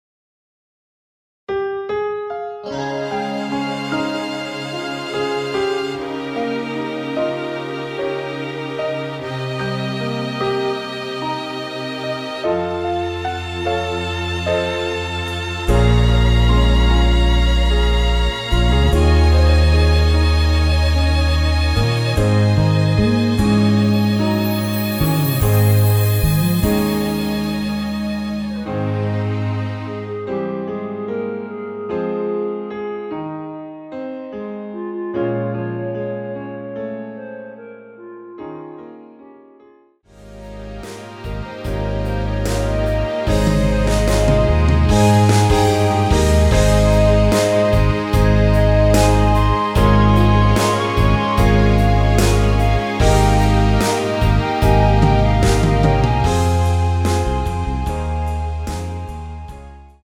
원키에서(+1)올린 멜로디 포함된 MR입니다.(미리듣기 확인)
Ab
멜로디 MR이라고 합니다.
앞부분30초, 뒷부분30초씩 편집해서 올려 드리고 있습니다.
중간에 음이 끈어지고 다시 나오는 이유는